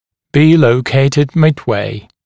[biː ləu’keɪtɪd ˌmɪd’weɪ][биː лоу’кейтид ˌмид’уэй]быть расположенным посередине